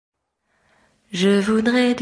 发音要注意有鼻音